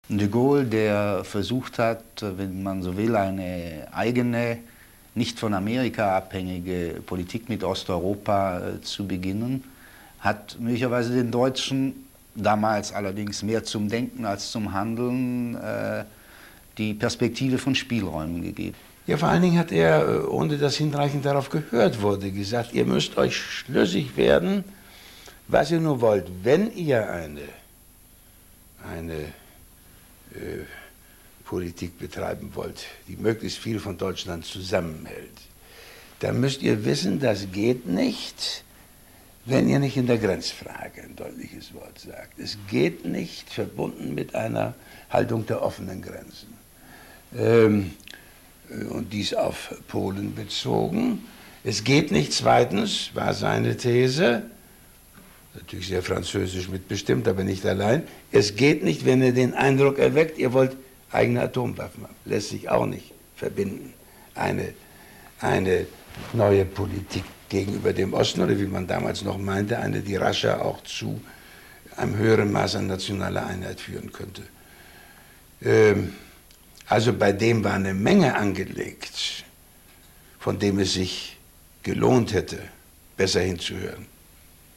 Excerpt from an interview with Willy Brandt conducted by Horst Schättle for the German TV broadcast “Zeugen des Jahrhunderts” (“Witnesses of the Century”) in December 1988